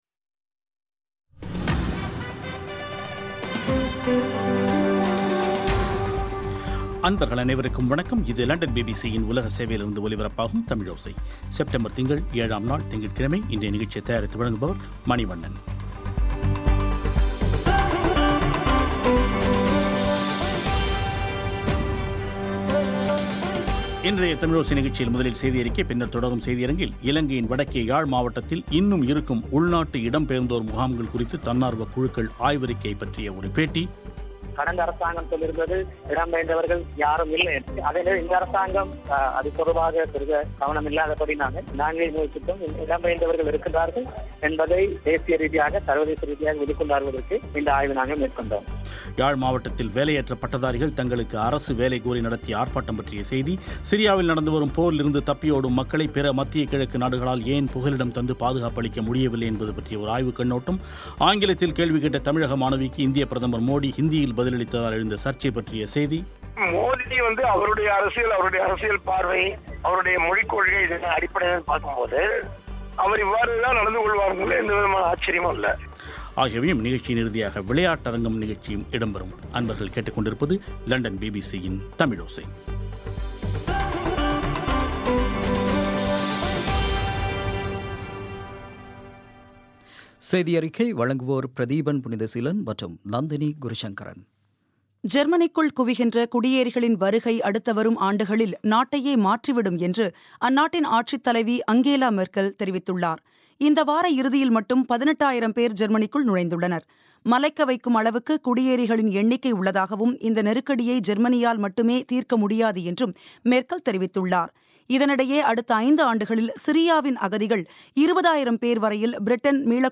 இலங்கையின் வடக்கே யாழ்மாவட்டத்தில் இன்னும் இருக்கும் உள்நாட்டு இடம் பெயர்ந்தோர் முகாம்கள் குறித்து தன்னார்வக் குழுக்கள் ஆய்வறிக்கை பற்றிய ஒரு பேட்டி